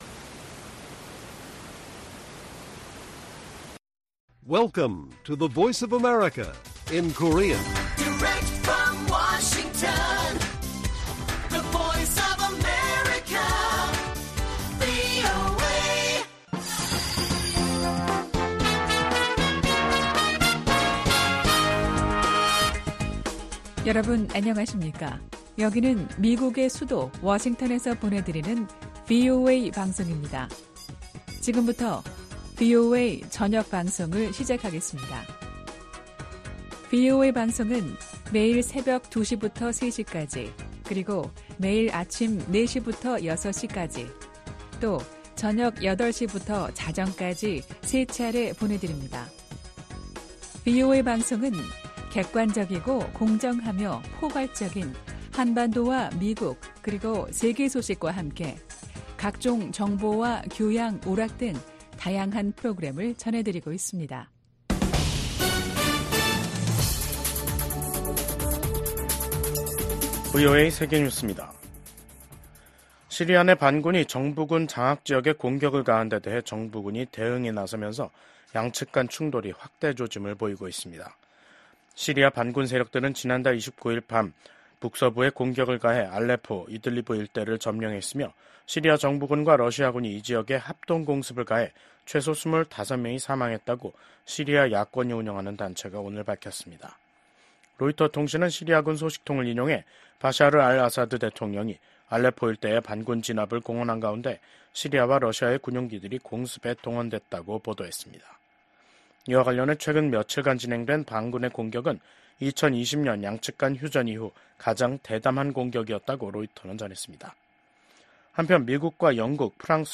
VOA 한국어 간판 뉴스 프로그램 '뉴스 투데이', 2024년 12월 2일 1부 방송입니다. 김정은 북한 국무위원장이 북한을 방문한 안드레이 벨로우소프 러시아 국방장관을 만나 우크라이나 전쟁과 관련해 러시아에 대한 지지 입장을 거듭 분명히 했습니다. 미국 국무부는 러시아 국방장관의 북한 공식 방문과 관련해 북러 협력 심화에 대한 우려 입장을 재확인했습니다.